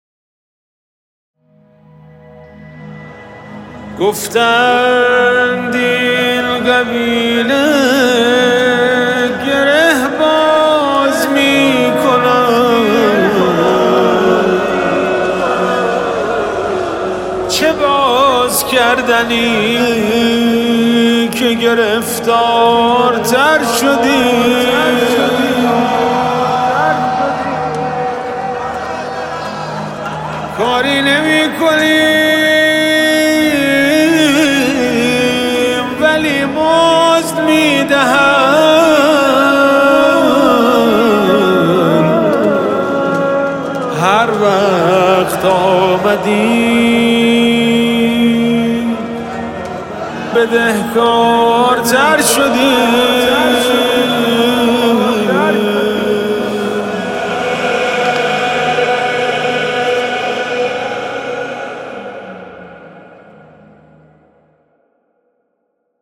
مذهبی
مناجات استودیویی با امام حسین (ع)